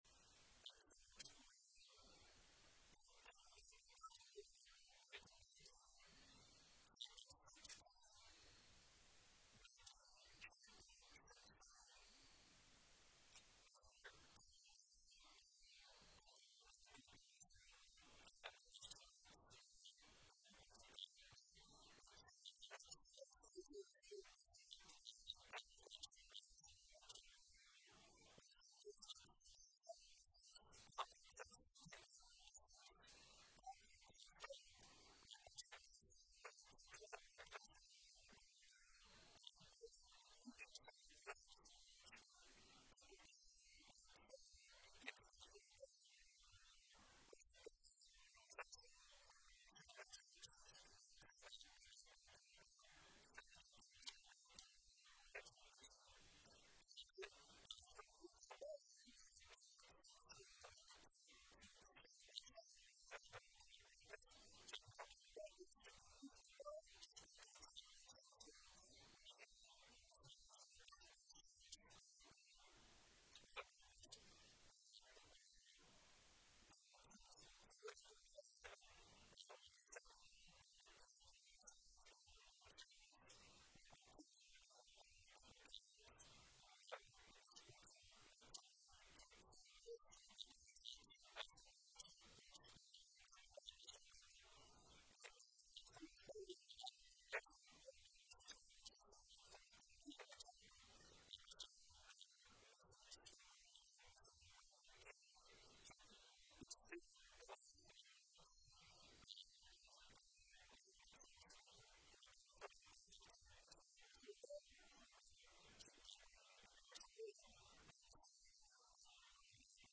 October 5, 2025 Sermon Audio.mp3